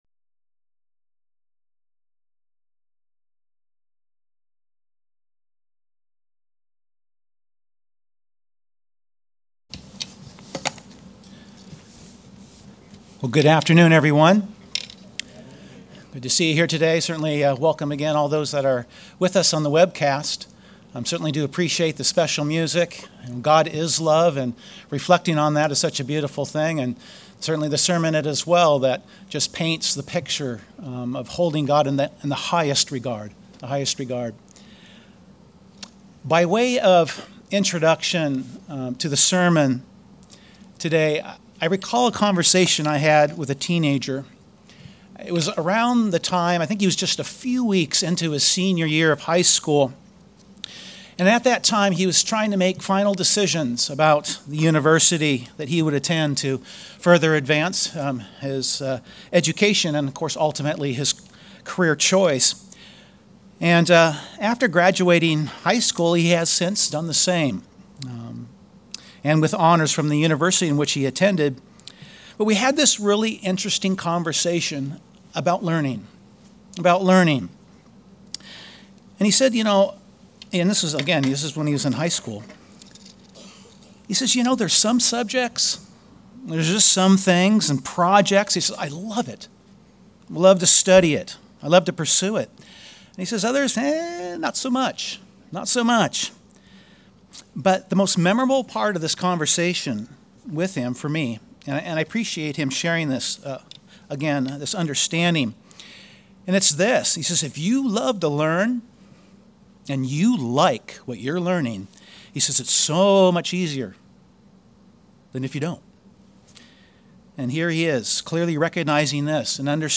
With the sermon time we will explore some of the traits of the teachable, what it looks like, how we benefit from it, while also seeing how we compare to those traits as we also look to see if we are honoring God with our willingness to learn and apply His teachings.